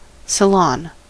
Ääntäminen
IPA : /səˈlɒn/